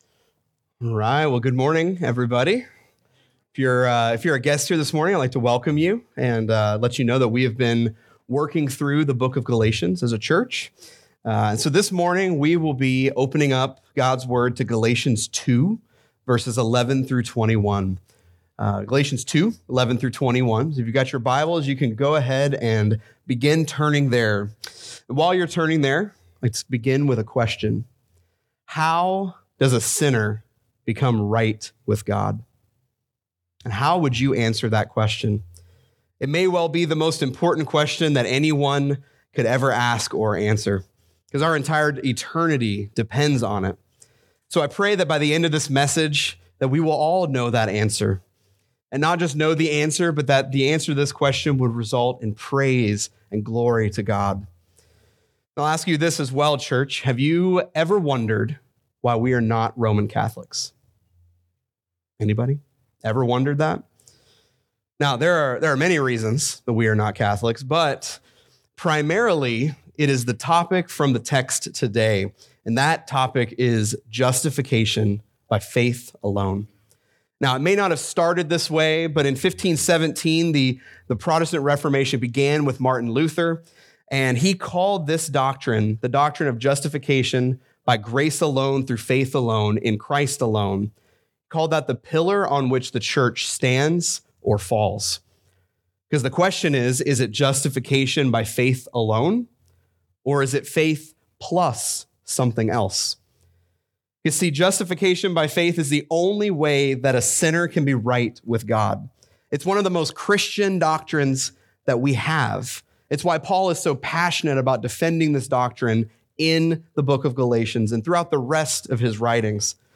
Check out this week's sermon as we unpack this…